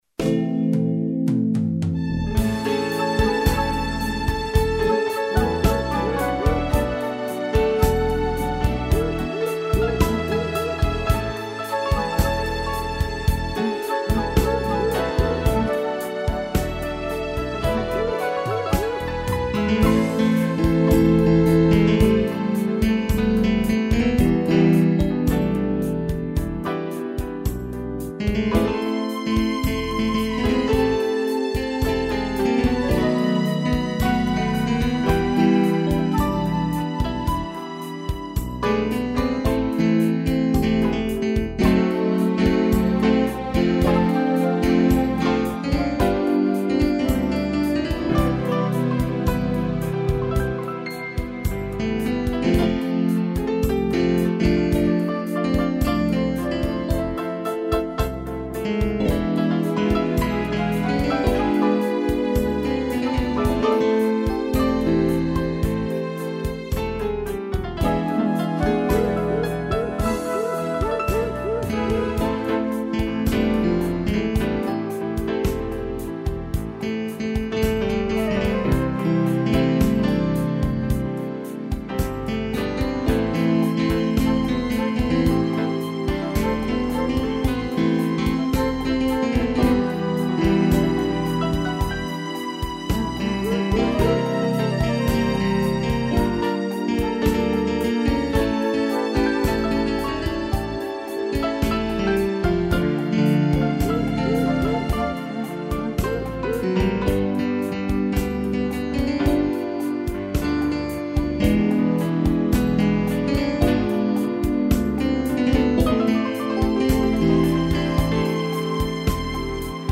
piano, strings, flauta e cuíca
(instrumental)